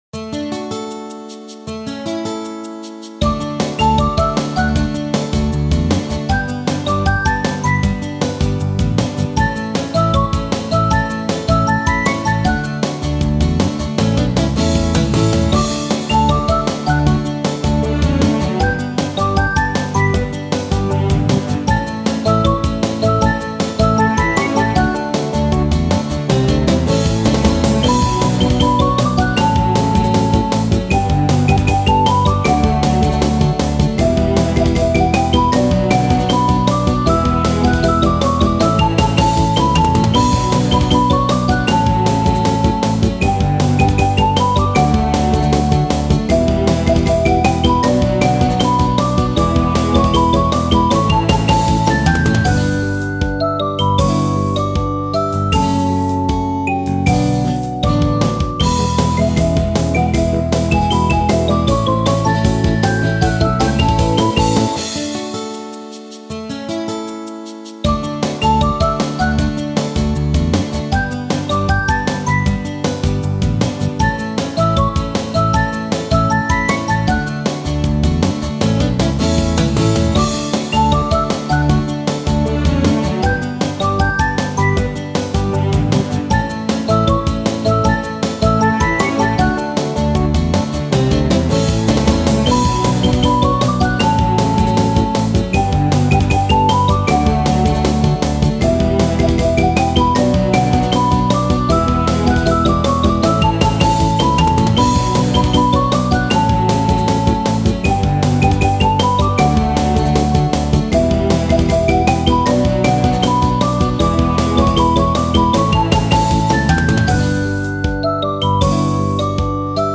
ogg(L) 街 爽快 明るい アップテンポ
軽快なギターとドラムに楽しげなメロディを乗せたポップな曲。